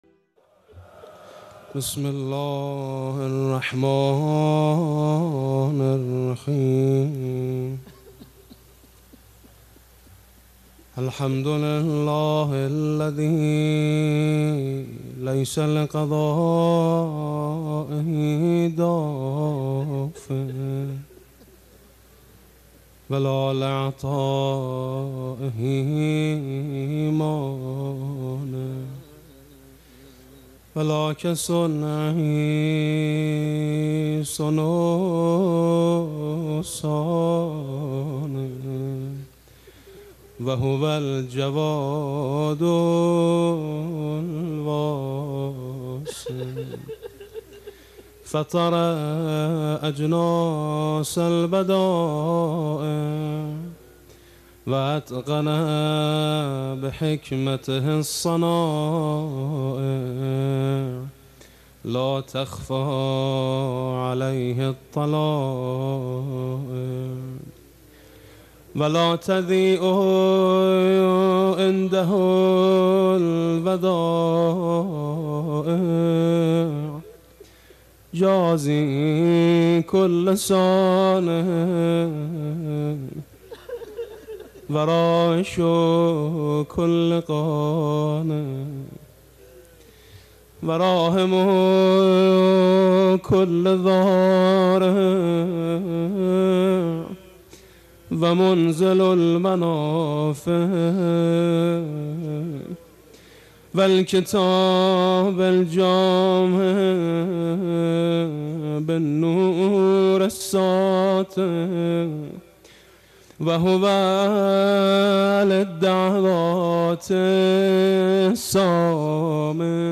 متن دعای عرفه بدون ترجمه + صوتی از علی فانی و فرهمند (دانلود دعای عرفه صوتی و متن , pdf) را در اینجا مشاهده کنید.